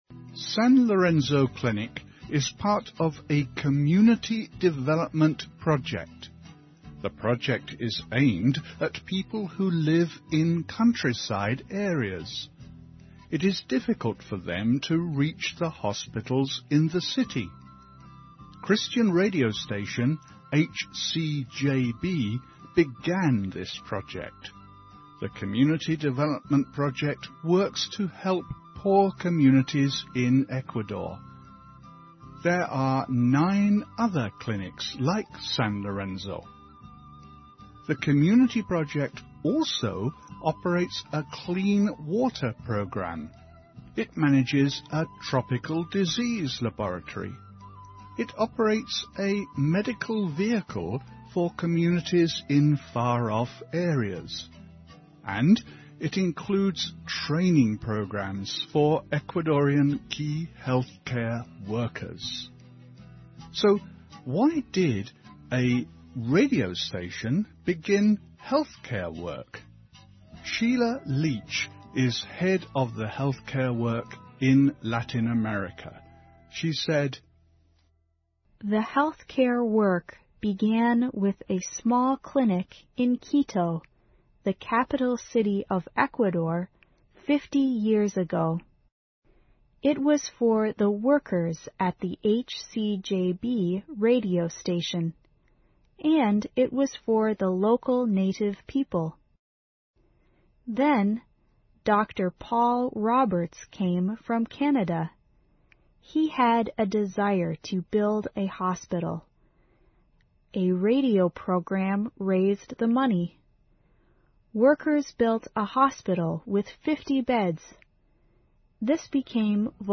环球慢速英语 第56期:医疗保健和广播(3)